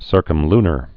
(sûrkəm-lnər)